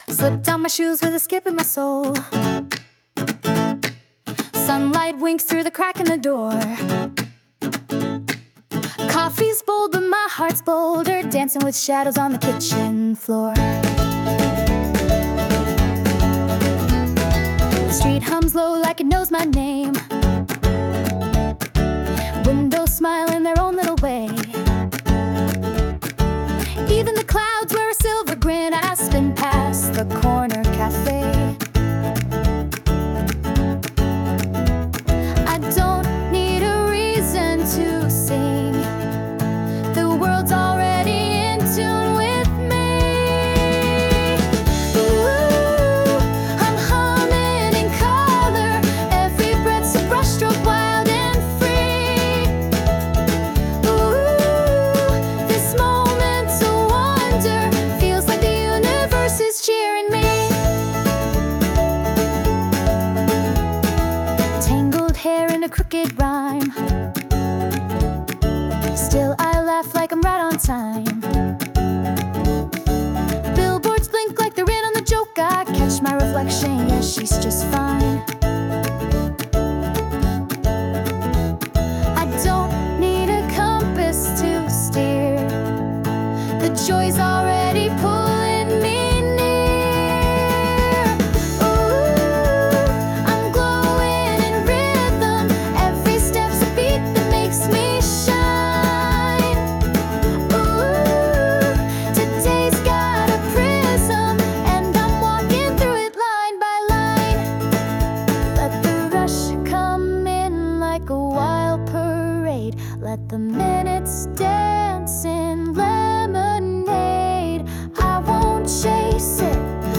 洋楽女性ボーカル著作権フリーBGM ボーカル
著作権フリーオリジナルBGMです。
女性ボーカル（洋楽・英語）曲です。